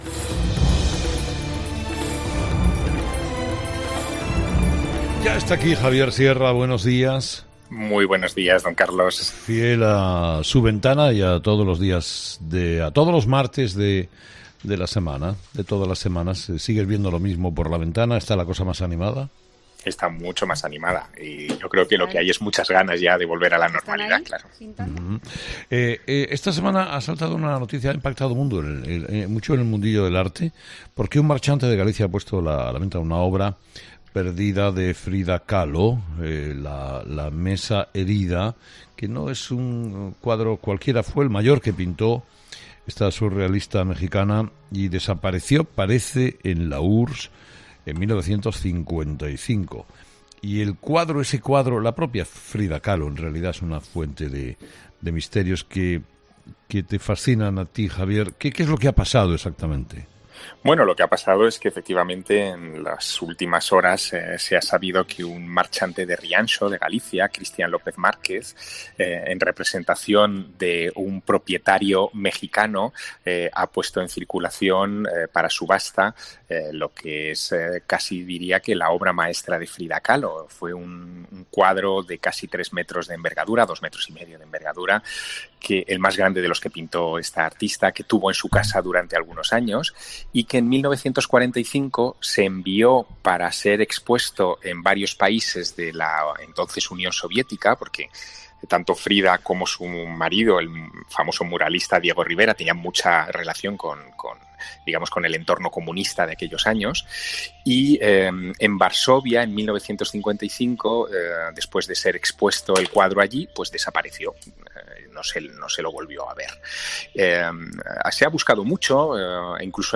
Javier Sierra, desde su ventana, nos cuenta los misterios que rodean al cuadro «La mesa herida» de Frida Kahlo que se encontraba en paradero desconocido desde 1955.